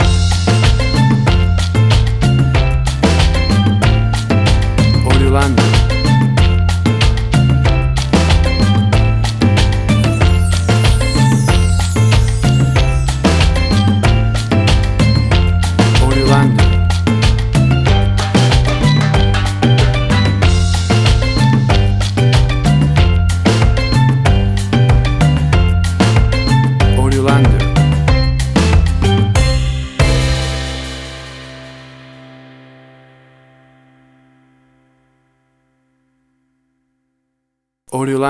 A fun and upbeat bouncy calypso island beat.
That perfect carribean calypso sound!
Tempo (BPM): 94